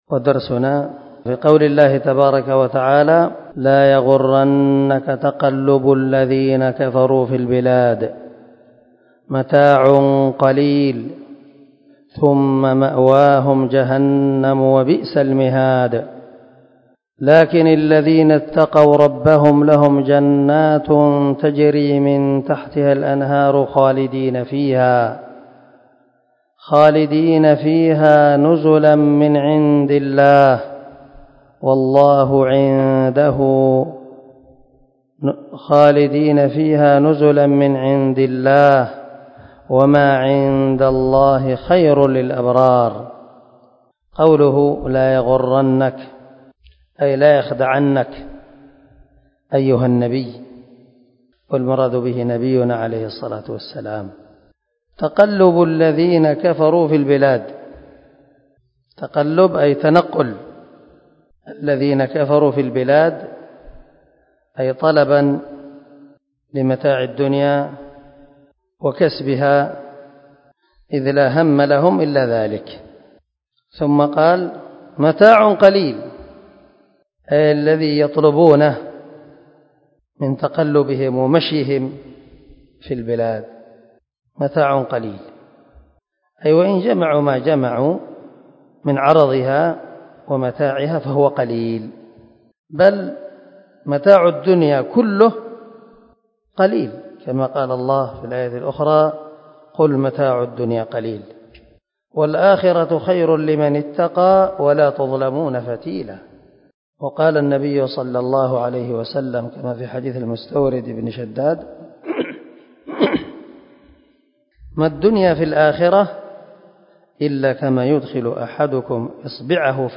231الدرس 76 تفسير آية ( 196 - 198 ) من سورة آل عمران من تفسير القران الكريم مع قراءة لتفسير السعدي